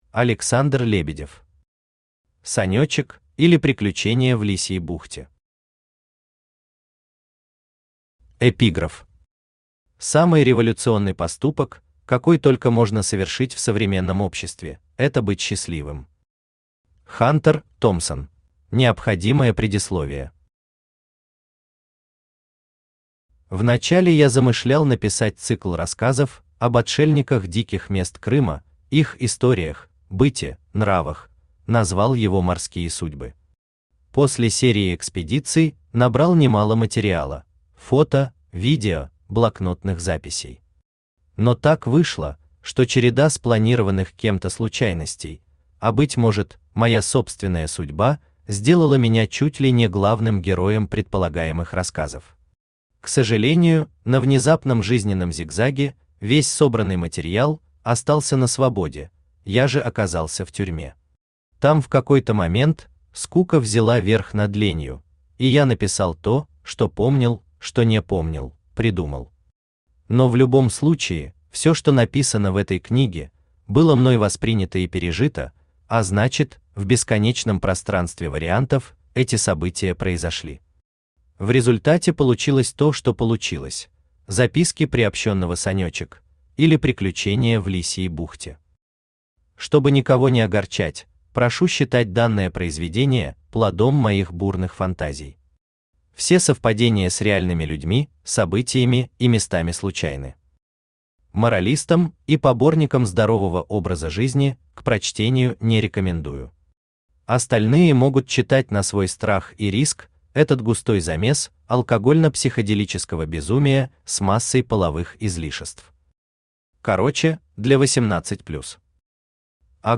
Аудиокнига Санёчек, или Приключения в Лисьей бухте | Библиотека аудиокниг
Aудиокнига Санёчек, или Приключения в Лисьей бухте Автор Александр Лебедев Читает аудиокнигу Авточтец ЛитРес.